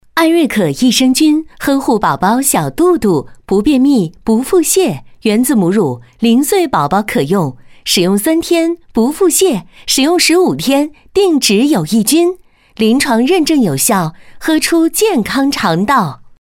Échantillons de voix natifs
Clean audio with no breaths or mouth noises
Styles: From conversational bright and friendly, to warm and authoritative.
Sennheiser MKH 416 Mic, UA Volt 276 Interface, Pro Recording Booth, Reaper
BarytonBasse
AutoritaireAmicalEngageantÉnergique